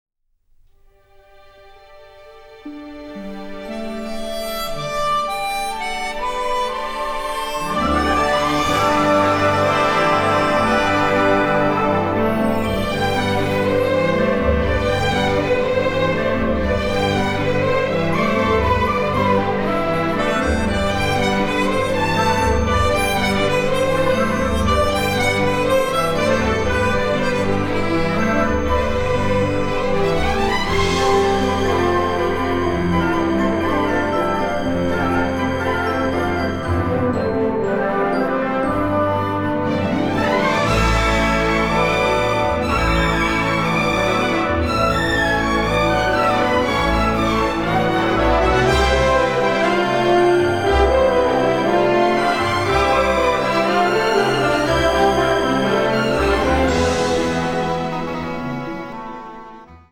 exciting, classic symphonic adventure score